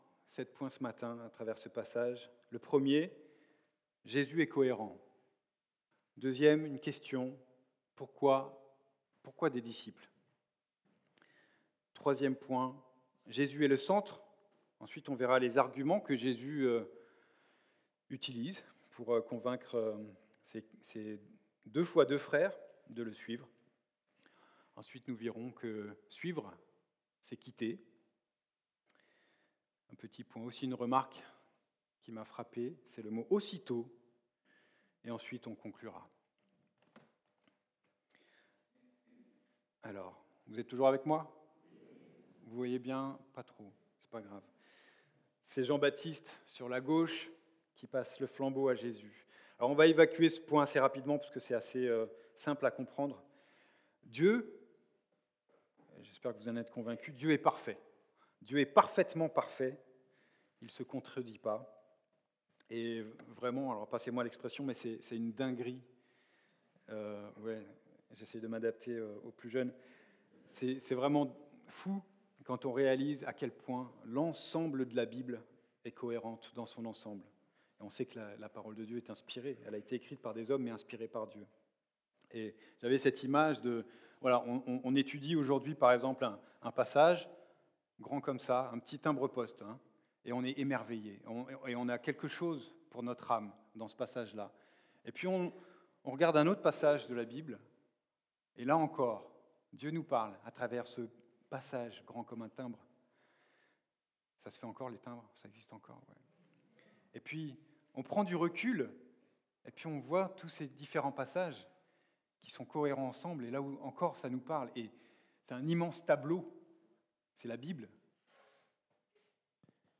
Culte du dimanche 22 Février 26
Prédications